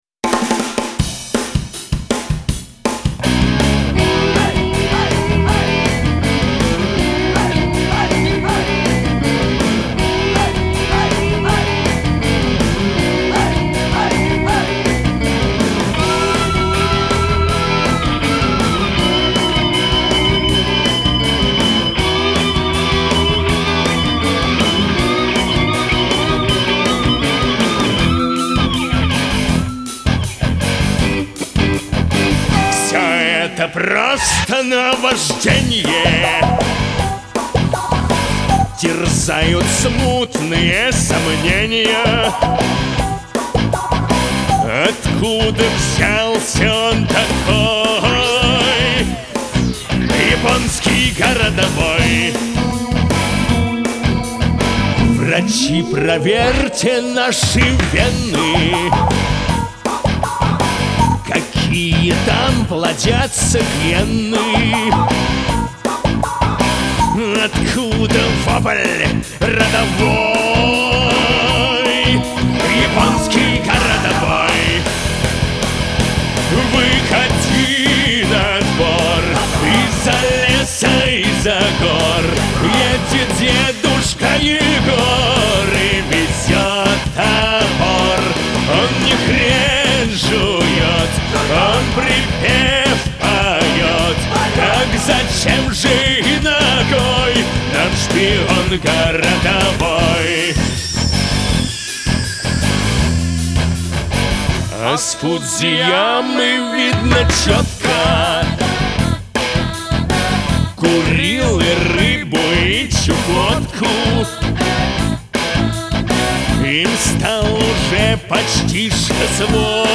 гусли, бэк-вокал.